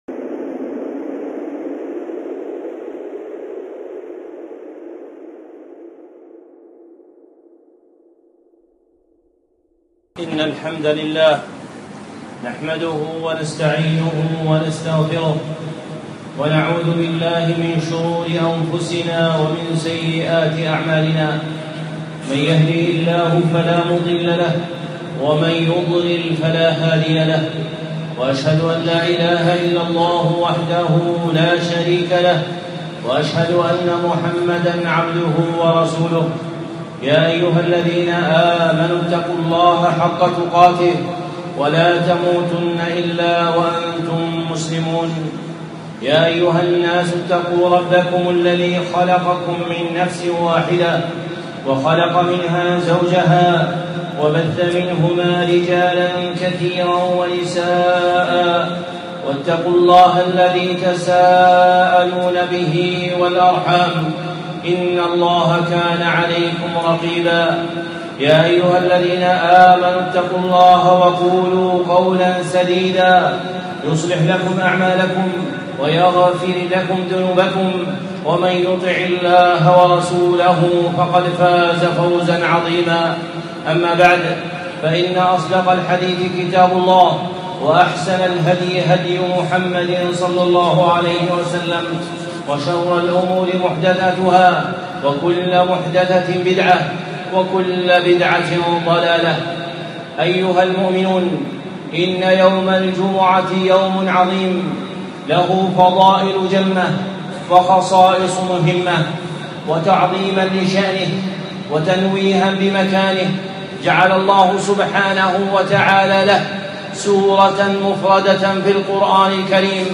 الخطب المنبرية